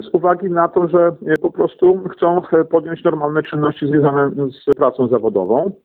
Burmistrz Latarowski dodaje, że zgłaszają się kolejni rodzice, którzy chcą, by ich dzieci wróciły do przedszkoli.